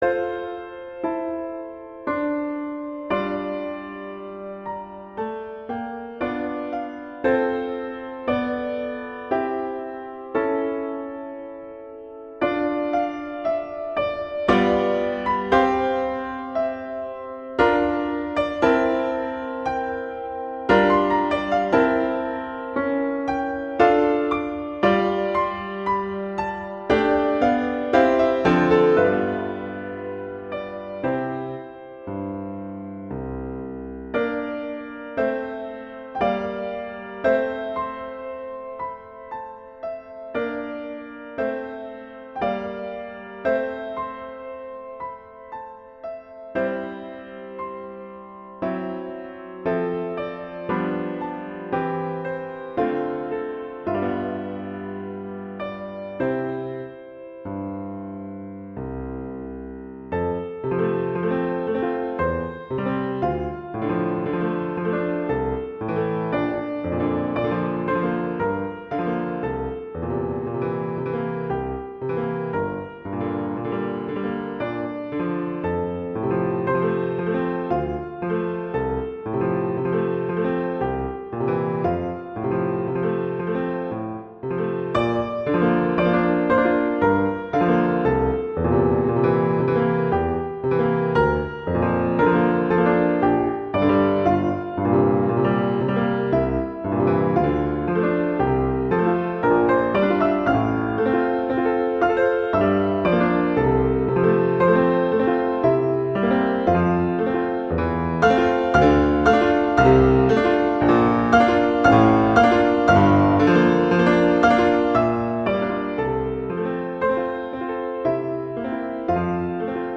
Instrumentation: piano solo
classical, wedding, festival, love
F major
♩=58 BPM